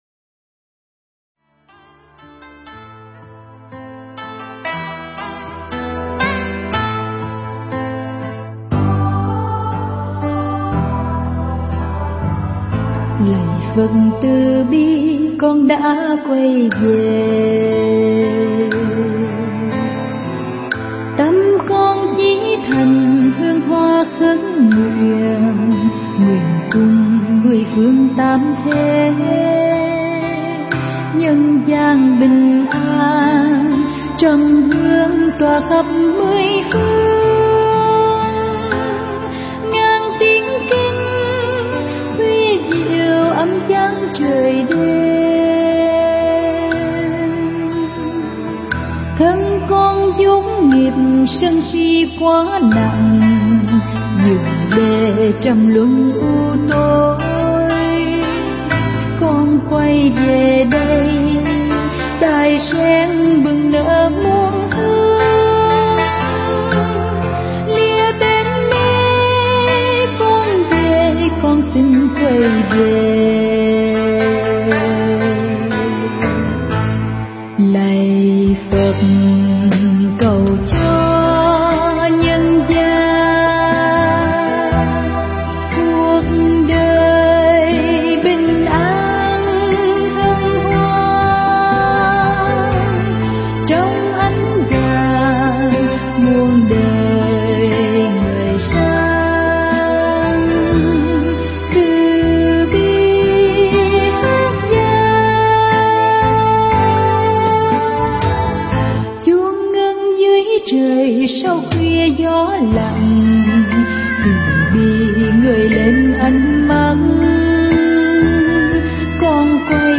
Mp3 Pháp Thoại Tịnh Độ Vấn Đáp 40
giảng tại Chùa Nam Thành